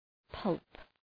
{pʌlp}